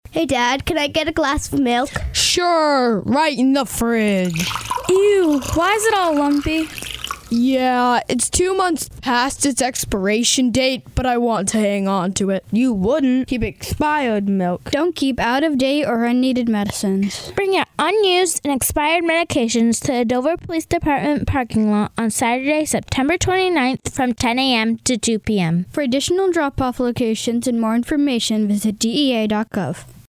The Coalition partnered with Dover Youth to Youth to develop a variety of radio and video PSAs educating youth about prescription drug abuse.